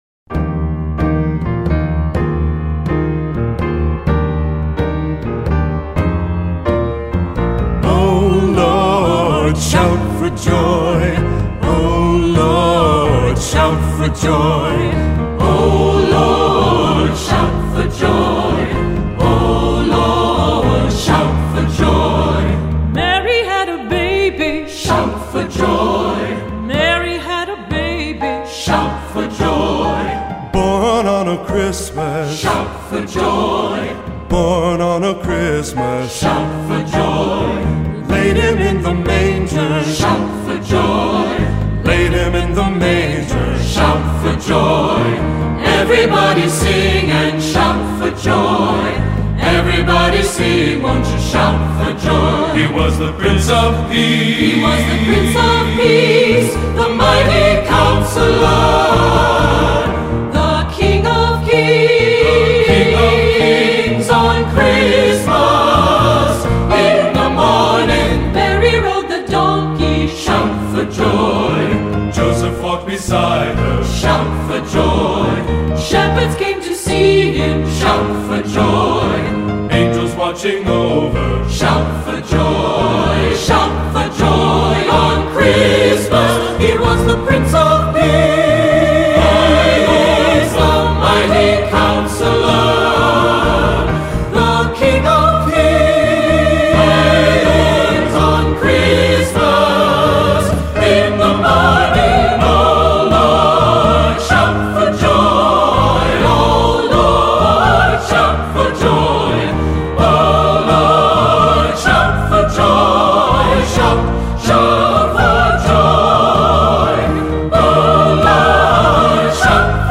Composer: Spiritual
Voicing: SATB